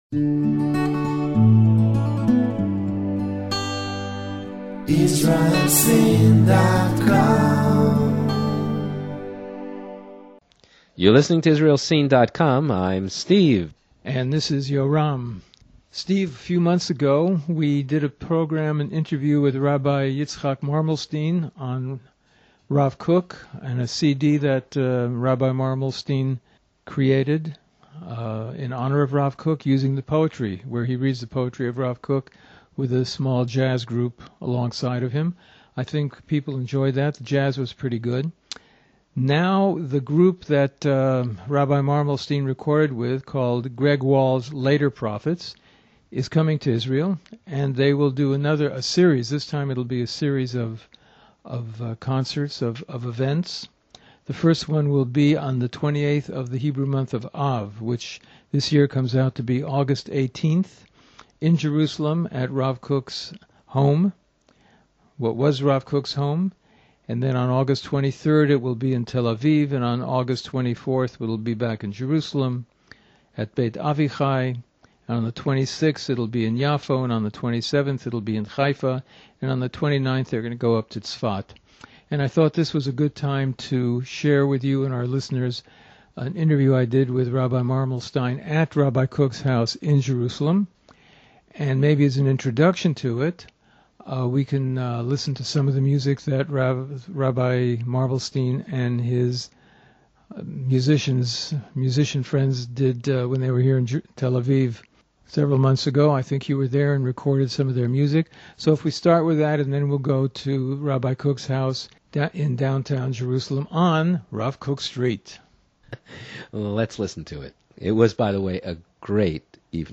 Tour of Rav Kook House in Jerusalem
Tour rav kookhouse.mp3